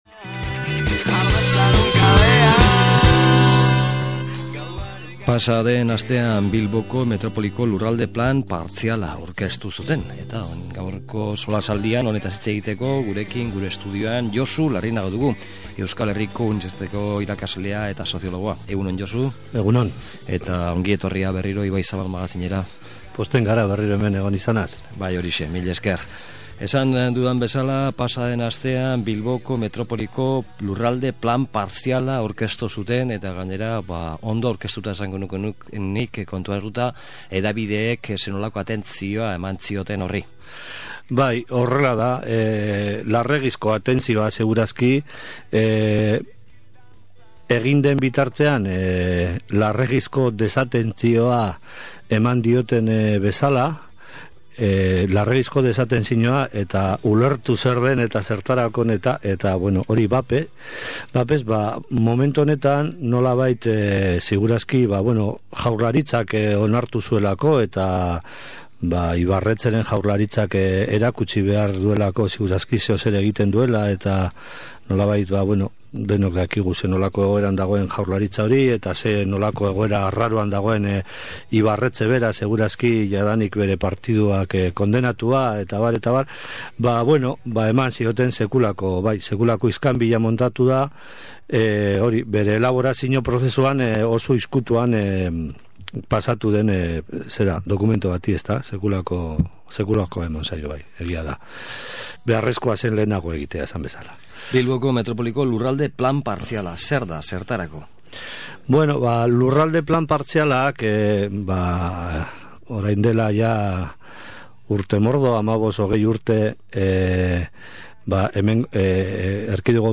SOLASALDIA: Bilboko Metropolirako Egitasmo Partziala dela eta